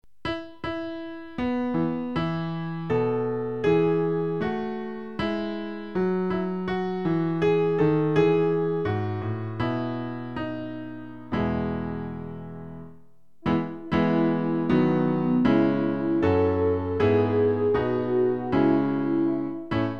Klavier-Playback zur Begleitung der Gemeinde
MP3 Download (ohne Gesang)